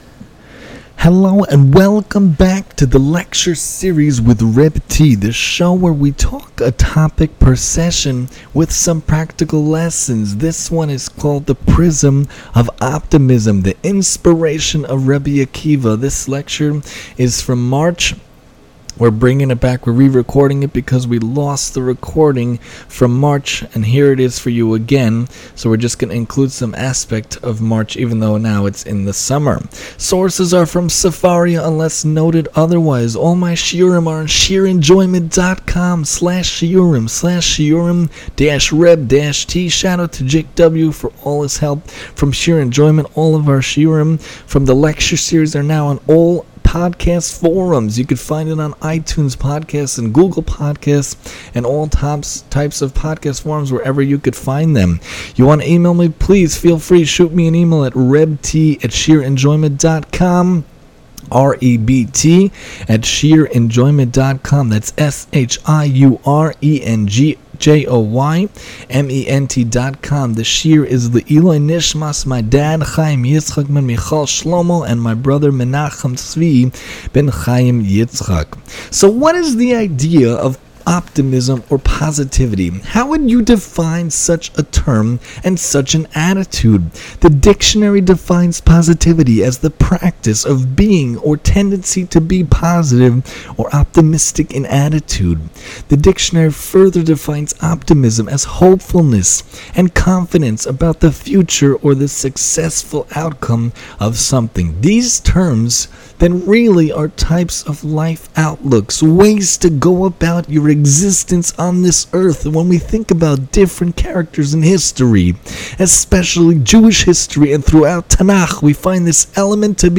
the prism of optimism, re-recorded lecture